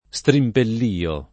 strimpellio [ S trimpell & o ] s. m.